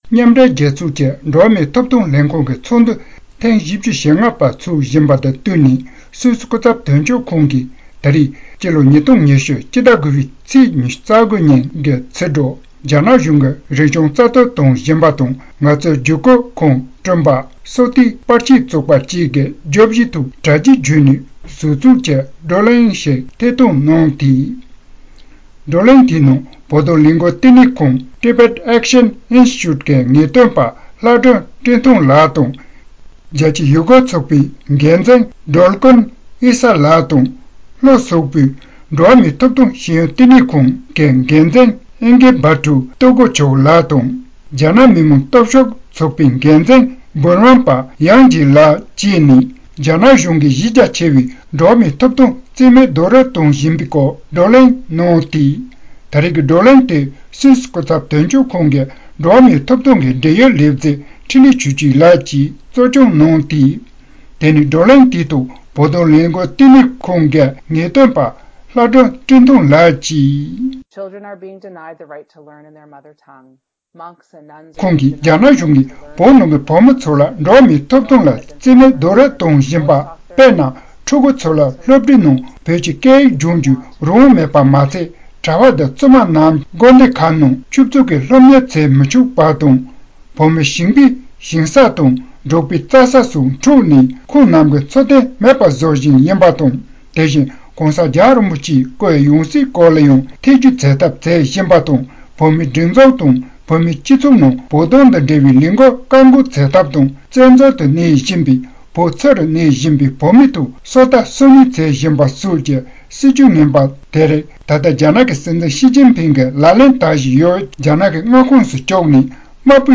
གནས་ཚུལ་ཕྱོགས་བསྒྲིགས་དང་སྙན་སྒྲོན་ཞུས་པར་གསན་རོགས་ཞུ།།